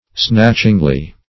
snatchingly - definition of snatchingly - synonyms, pronunciation, spelling from Free Dictionary Search Result for " snatchingly" : The Collaborative International Dictionary of English v.0.48: Snatchingly \Snatch"ing*ly\, adv. By snatching; abruptly.